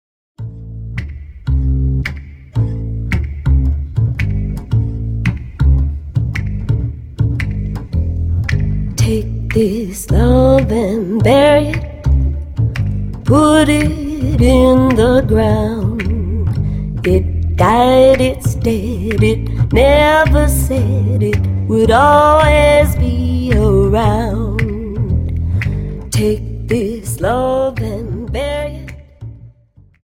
Dance: Slowfox Song